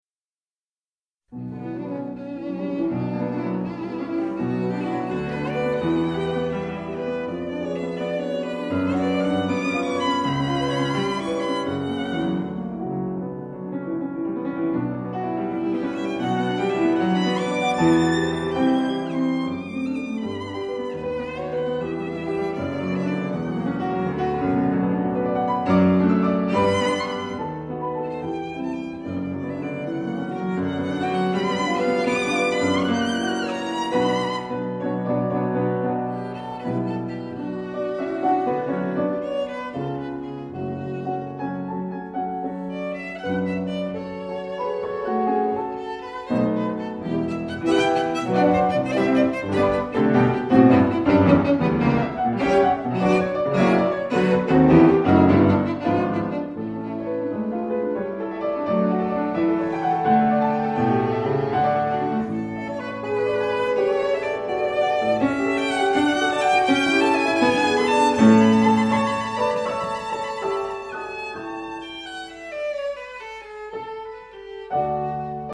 Musique romantique
1) Allegro